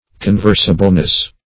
Search Result for " conversableness" : The Collaborative International Dictionary of English v.0.48: Conversableness \Con*vers"a*ble*ness\, n. The quality of being conversable; disposition to converse; sociability.
conversableness.mp3